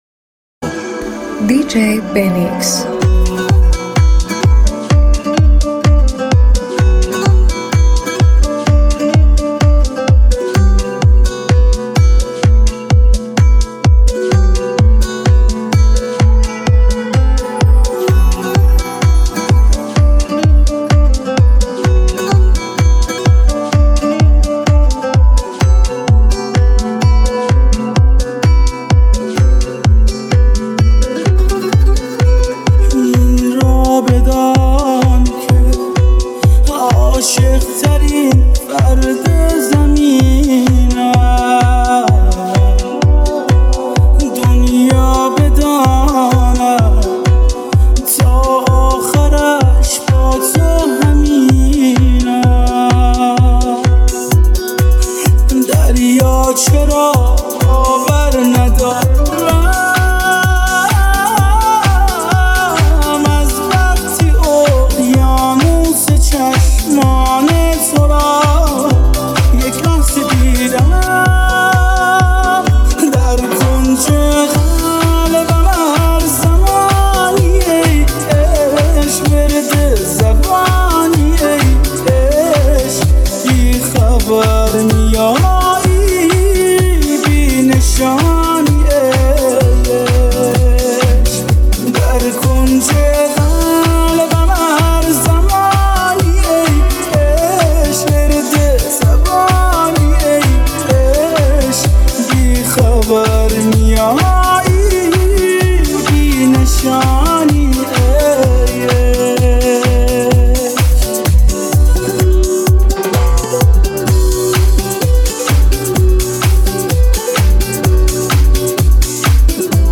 جذاب و پرانرژی
ملودی‌های احساسی